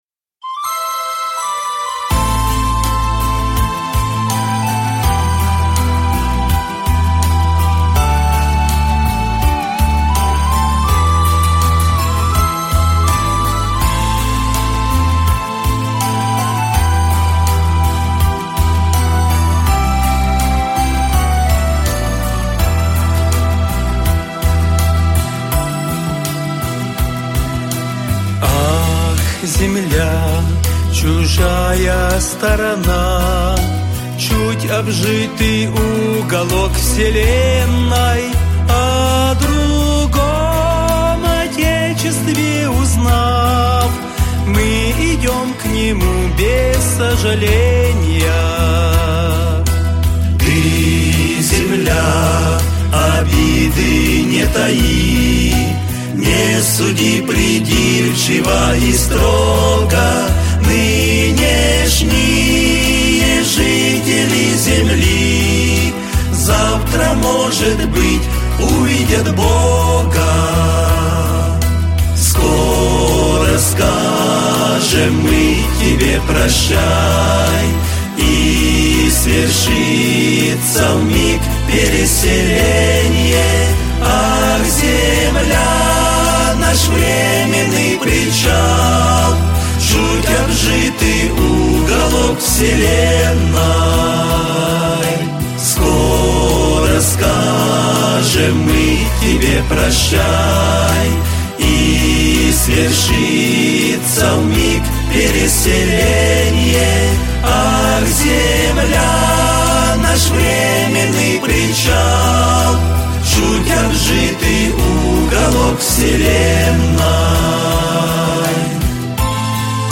727 просмотров 1521 прослушиваний 174 скачивания BPM: 82